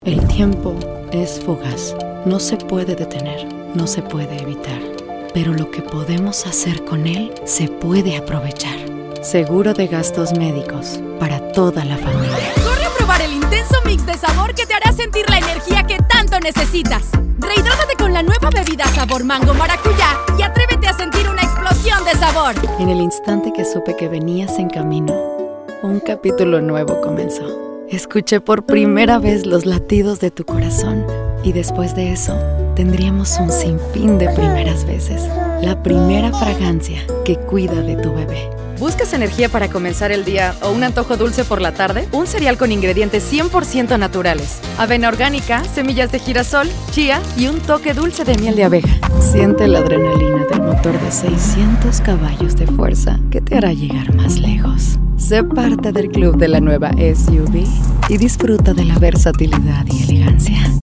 Doblaje
Locución
Soy una locutora comercial, locutora de cabina y actriz de doblaje mexicana que ha enfocado su carrera en el manejo del acento neutro.
Cuento con acceso a Home Studio.